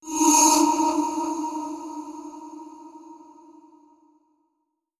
Jumpscare_10.wav